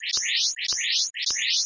啁啾的噪声波 " 啁啾的白噪声与敲击声
描述：我用来制作机器人鸣叫的短波来自...
Tag: 噪声 产生啁啾 高音调 噪声 合成的